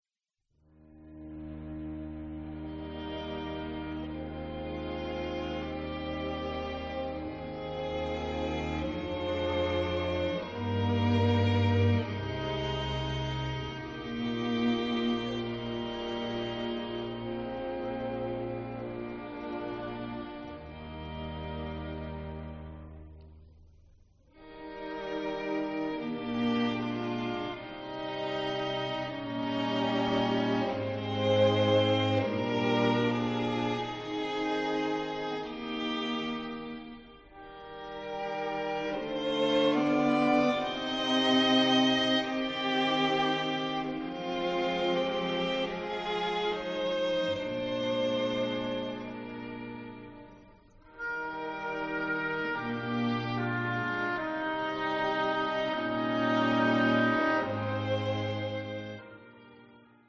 Violin
Viola
Violincello
Contrabass
Harp
Recording Location 덴덴홀 에로라(일본 사이타마현 마츠부시마치)